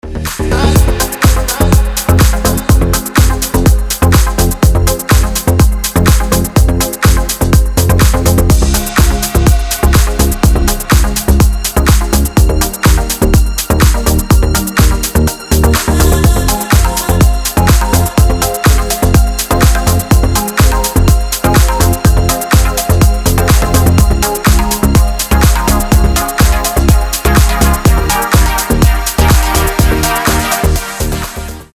• Качество: 320, Stereo
ритмичные
громкие
deep house
индийские
Хорошая дип-хаус музыка от индийского диджея